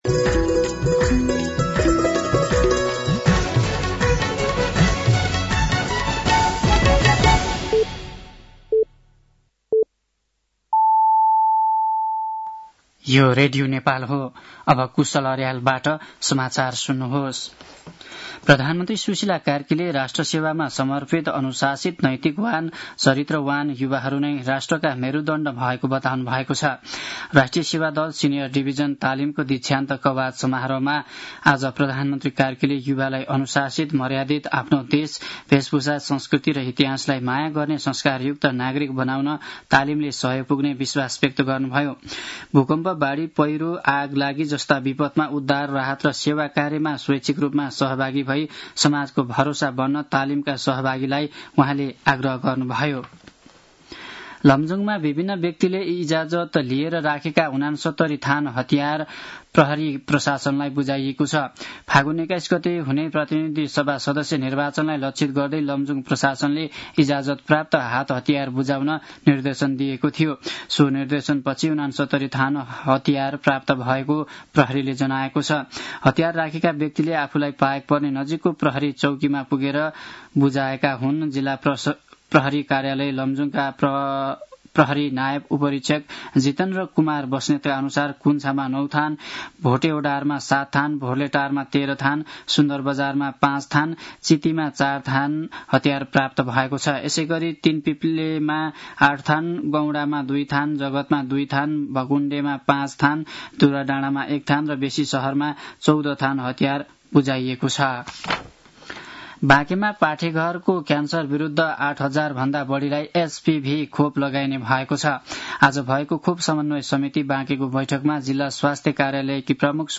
साँझ ५ बजेको नेपाली समाचार : २० माघ , २०८२